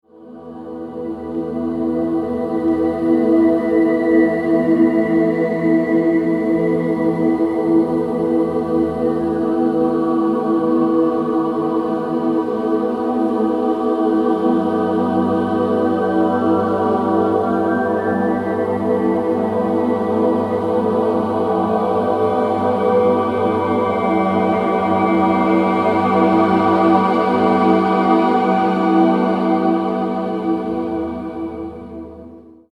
Australian, Vocal